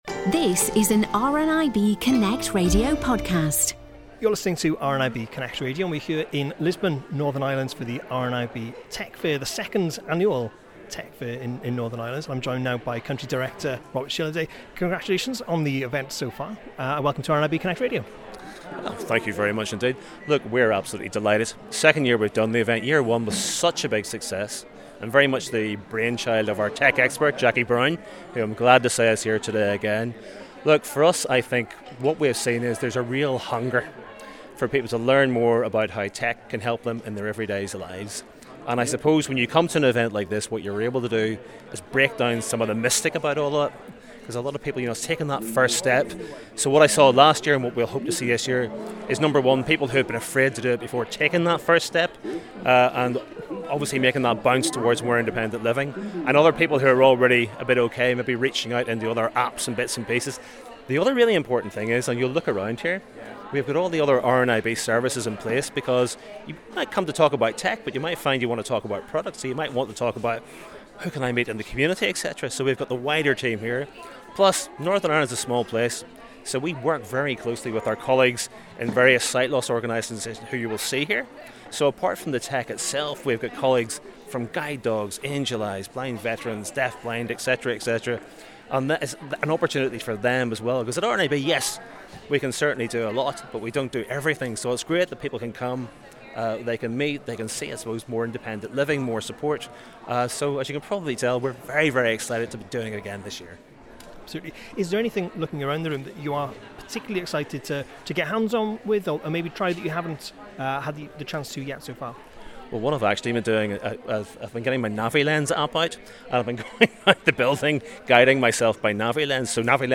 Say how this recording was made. at this year’s Northern Ireland Tech Fair in Lisburn.